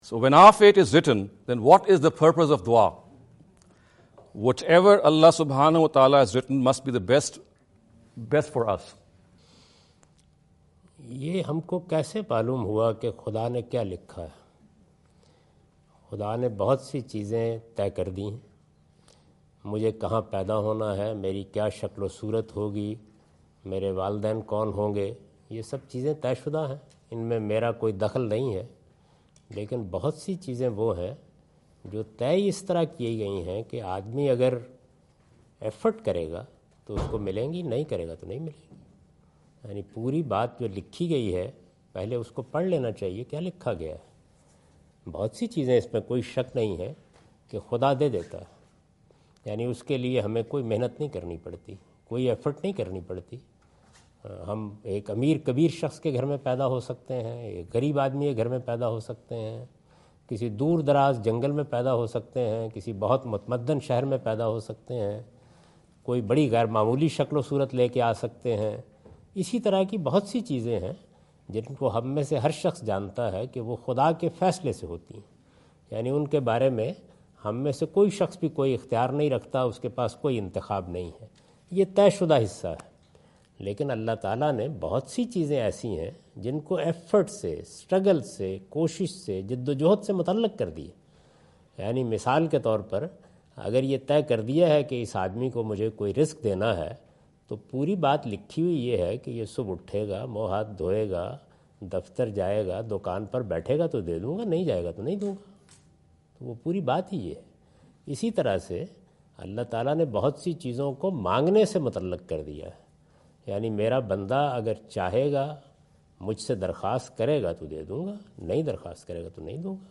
Category: English Subtitled / Questions_Answers /
In this video Javed Ahmad Ghamidi answer the question about "purpose of supplications" asked at East-West University Chicago on September 24,2017.